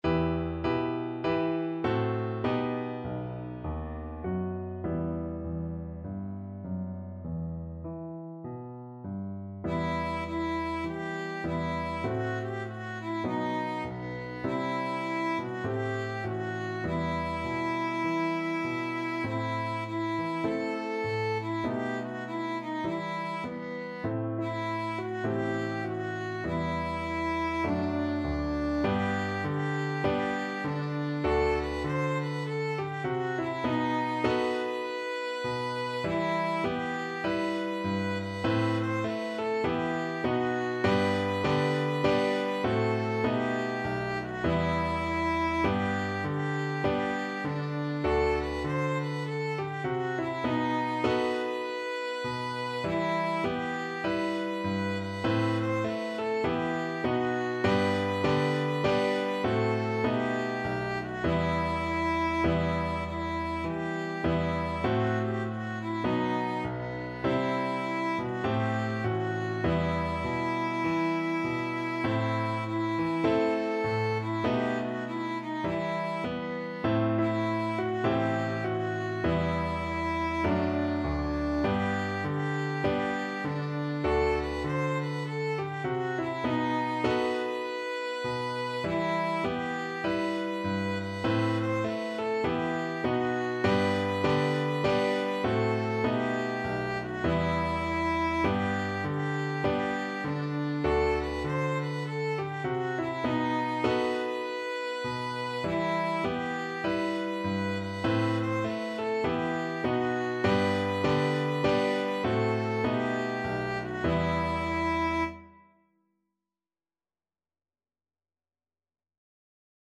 Violin version
Traditional Violin